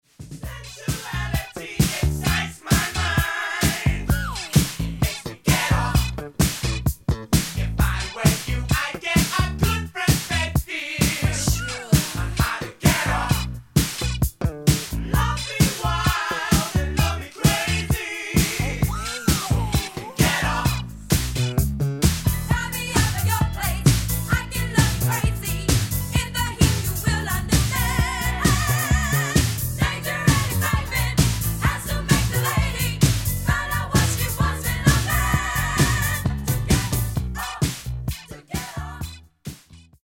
Genere:   Disco | Funky